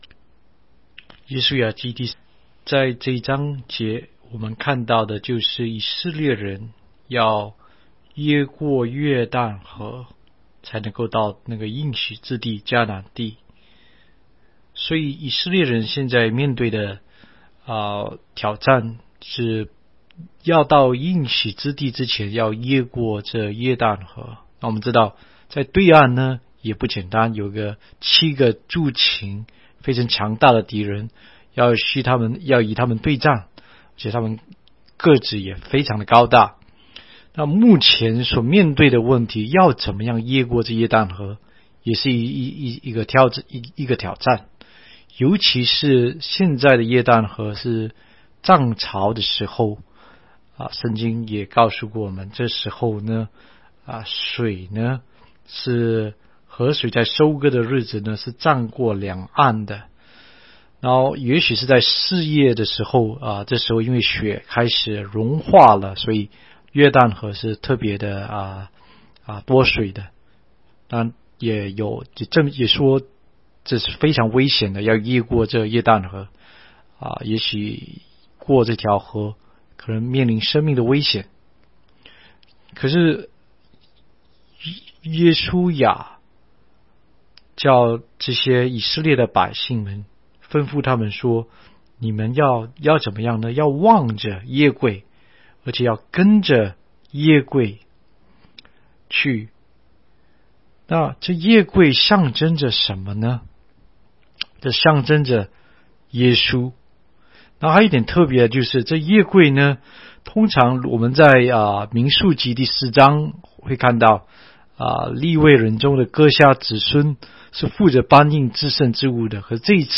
16街讲道录音 - 每日读经-《约书亚记》3章